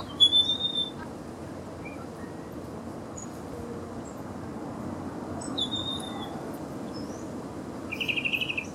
Federal (Amblyramphus holosericeus)
Nombre en inglés: Scarlet-headed Blackbird
Localidad o área protegida: Reserva Ecológica Costanera Sur (RECS)
Condición: Silvestre
Certeza: Fotografiada, Vocalización Grabada